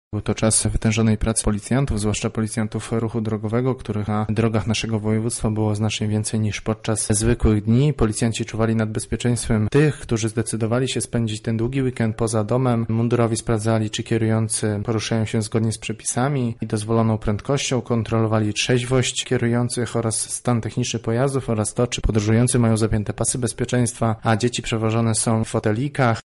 O tym, na co mundurowi zwracali największą uwagę podczas kontroli, wyjaśnia sierżant sztabowy